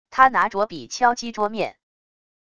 他拿着笔敲击桌面wav音频